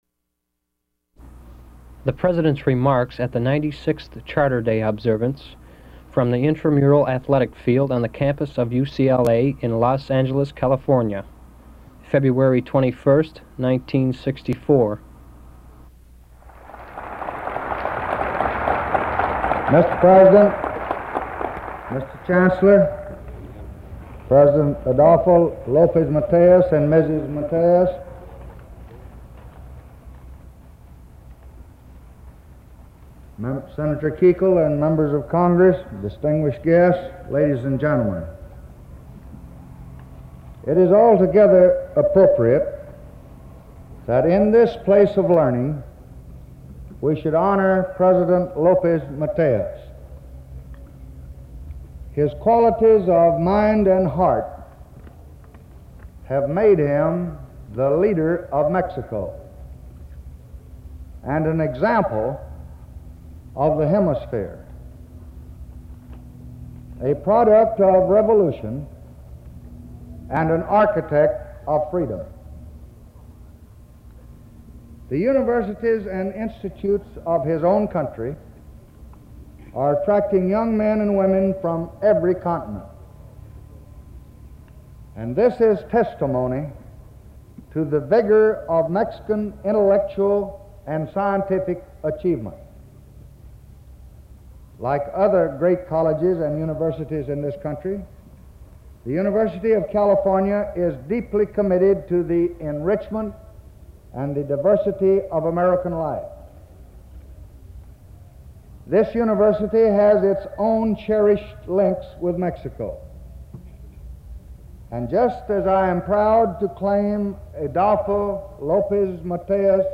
Presidential Speeches | Lyndon B. Johnson Presidency